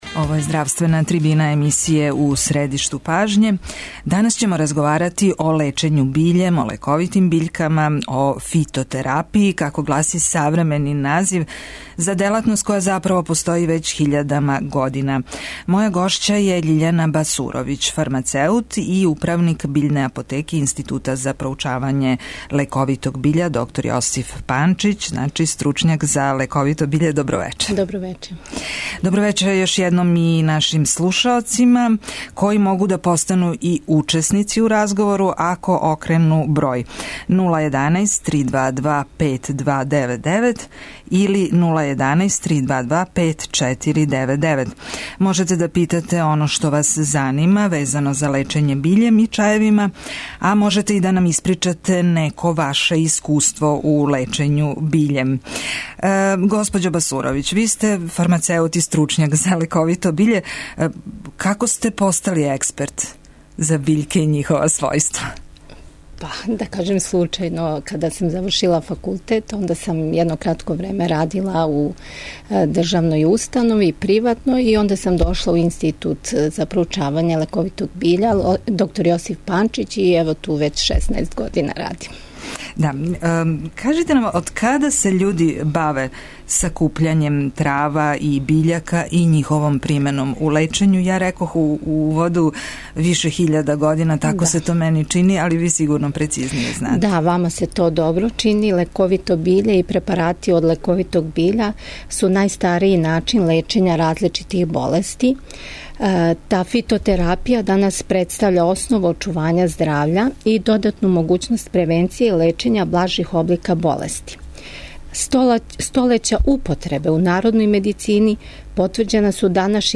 Слушаоци су позвани да се укључе у разговор и поставе питање или да испричају неко своје искуство везано за дејство биљака у процесу лечења.